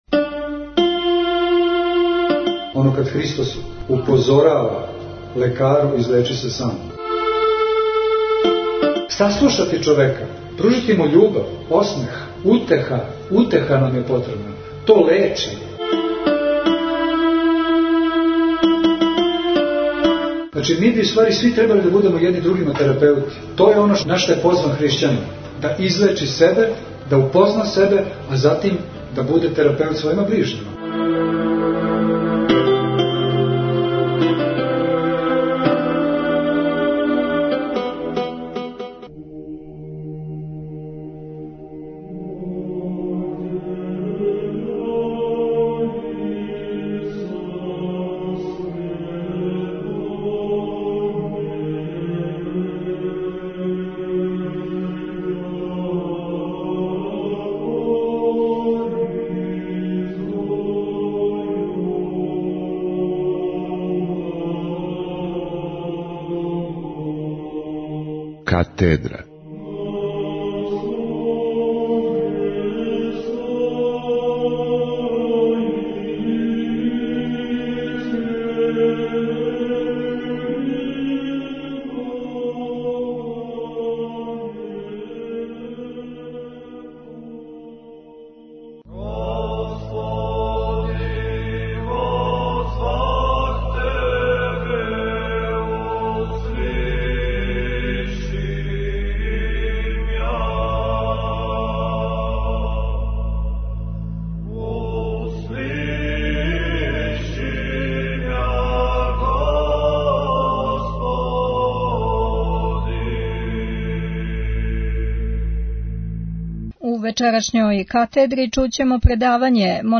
Предавање